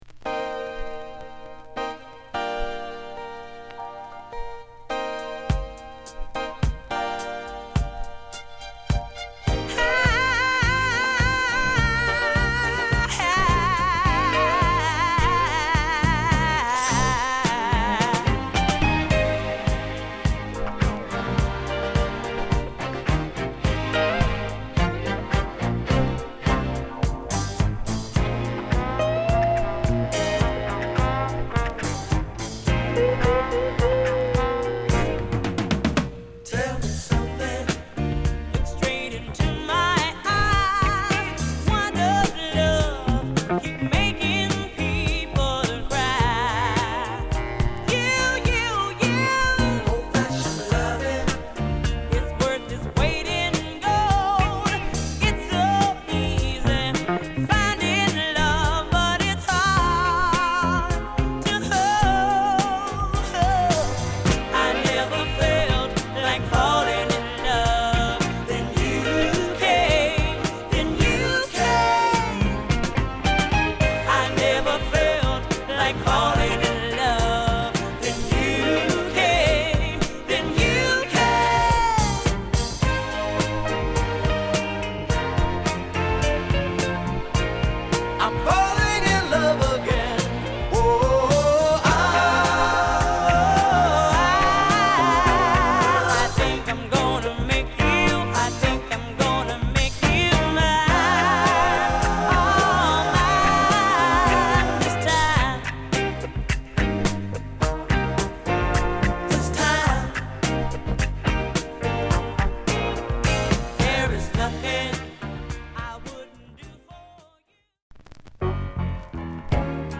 メロウからディスコまで充実の1枚！